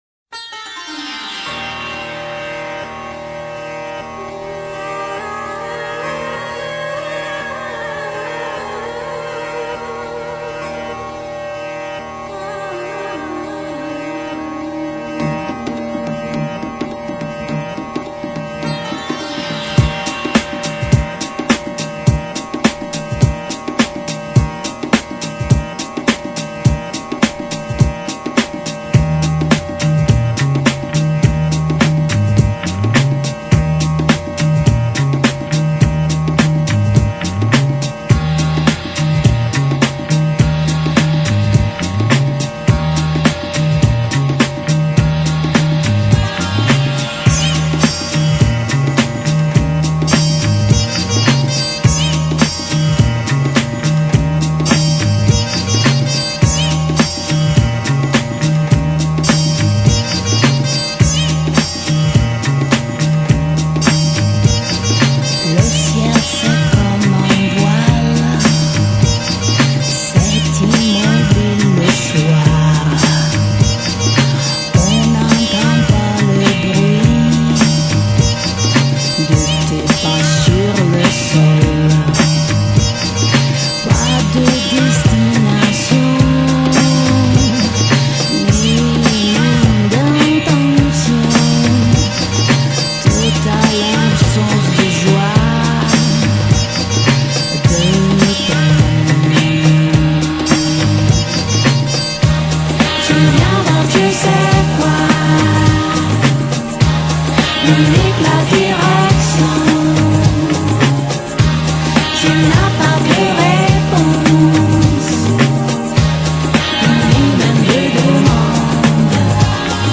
欧洲最畅销小酒馆音乐，坚强的阵容全员集合
超级DJ找来慵懒女声混杂中东音色惊异之作
义大利王牌DJ组合融合轻爵士与巴莎诺瓦之作
融合70年代灵魂与现代电音的奇才